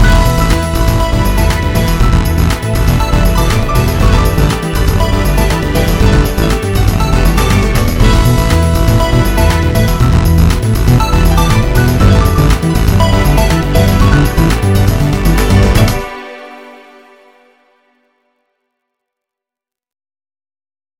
MIDI 29.14 KB MP3